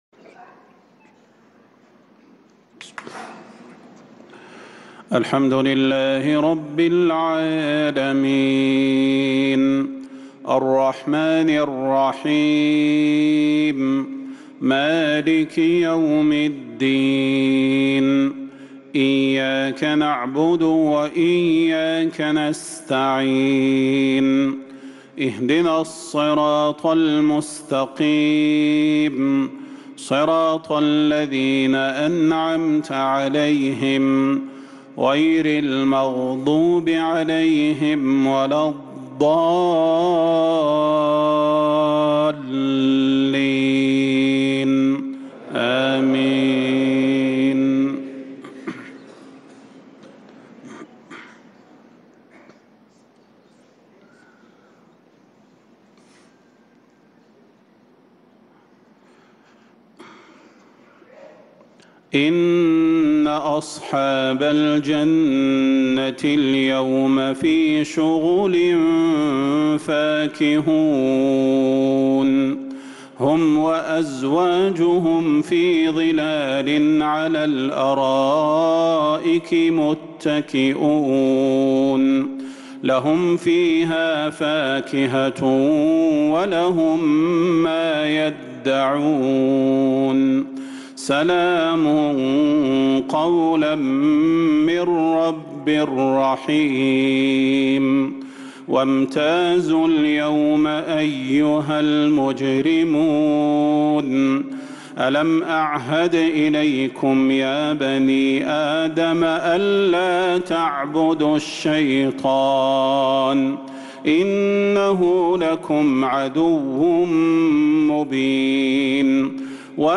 إصدار جميع تلاوات الشيخ صلاح البدير في شهر جمادى الأولى - الآخرة 1446هـ > سلسلة الإصدارات القرآنية الشهرية للشيخ صلاح البدير > الإصدارات الشهرية لتلاوات الحرم النبوي 🕌 ( مميز ) > المزيد - تلاوات الحرمين